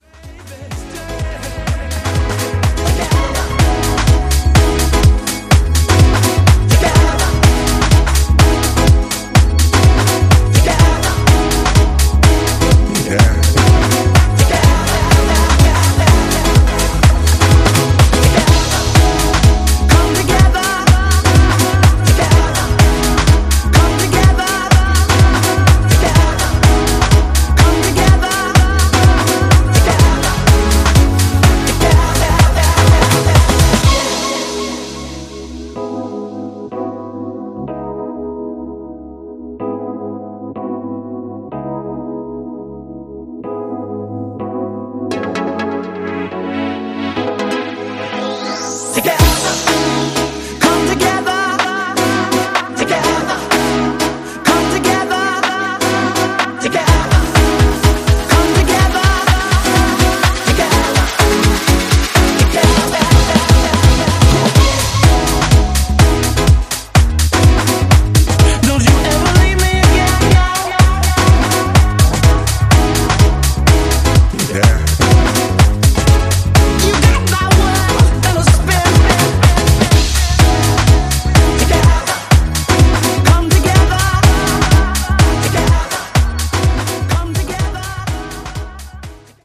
Dub Mix